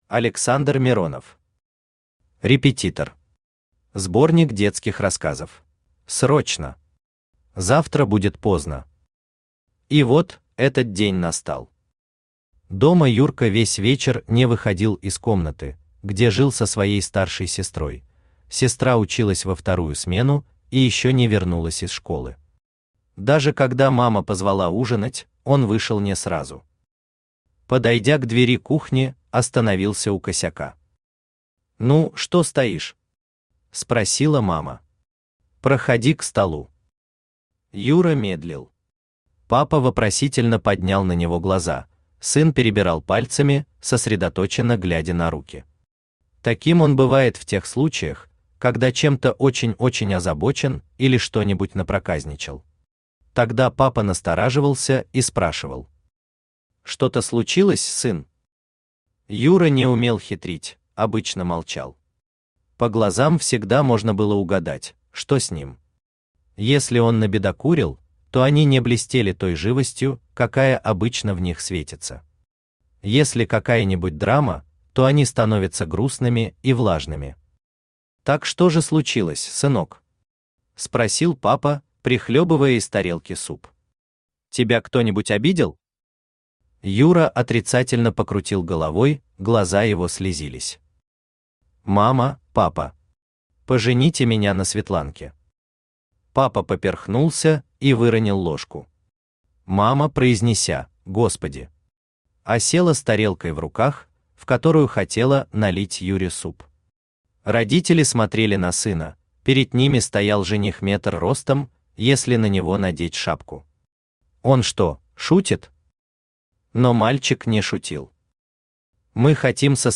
Аудиокнига Репетитор. Сборник детских рассказов | Библиотека аудиокниг
Сборник детских рассказов Автор Александр Леонидович Миронов Читает аудиокнигу Авточтец ЛитРес.